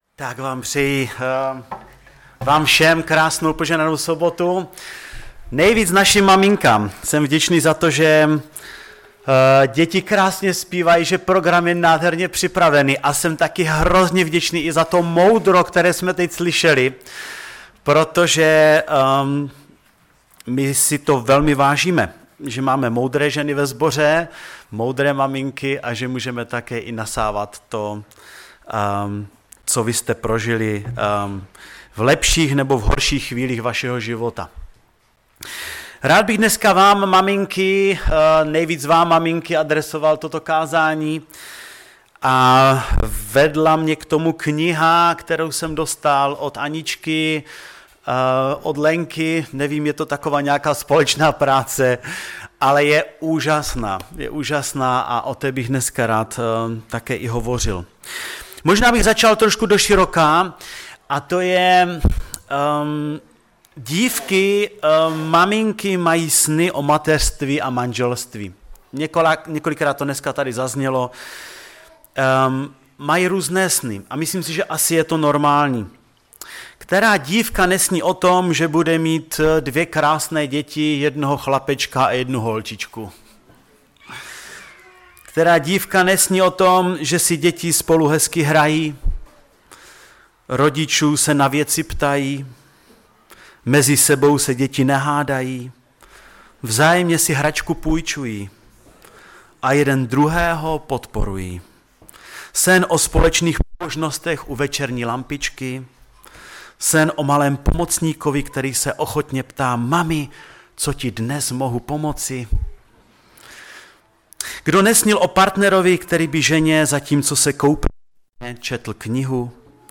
Kázání
Kazatel